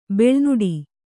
♪ bīḷu nuḍi